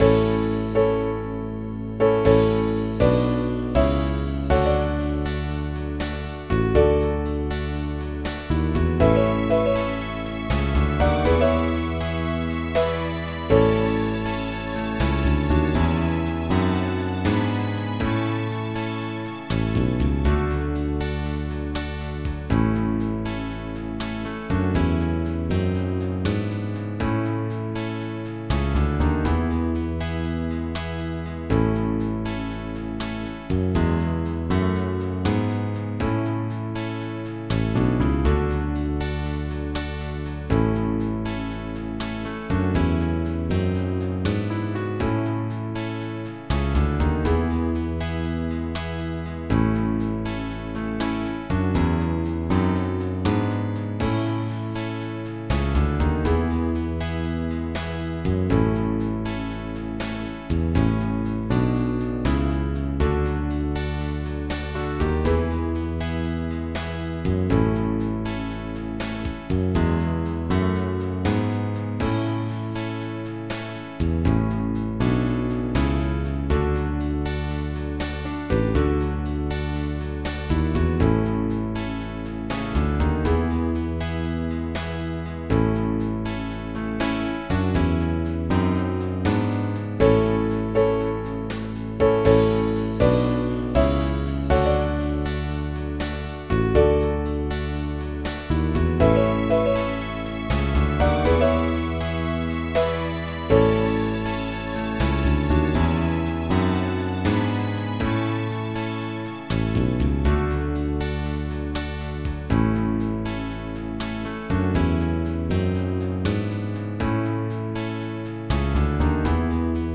Oldies
Type General MIDI